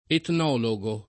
[ etn 0 lo g o ]